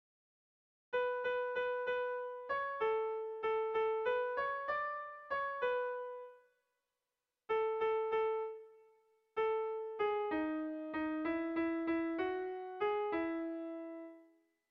Air de bertsos - Voir fiche   Pour savoir plus sur cette section
Irrizkoa
Bertsoaren bigarren puntua errepikatuz darrai aurrera.
AB